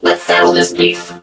mech_crow_start_01.ogg